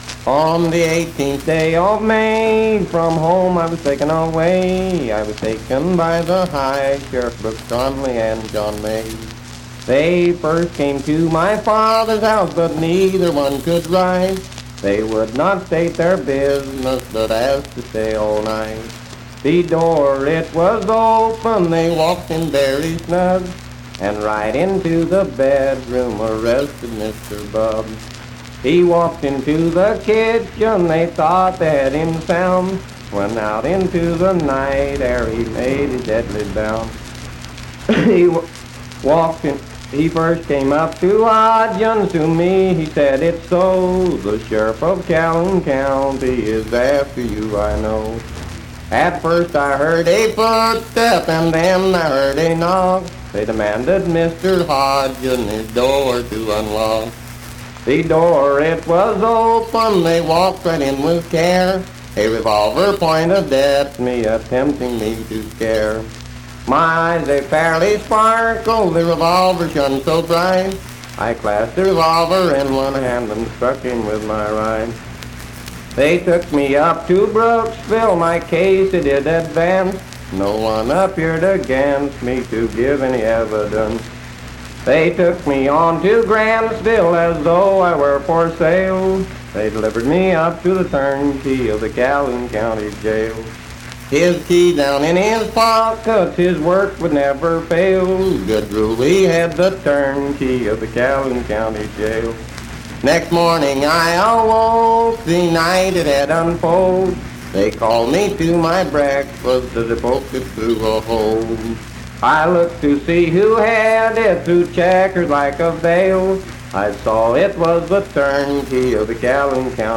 Unaccompanied vocal performance
Verse-refrain 14d(2).
Voice (sung)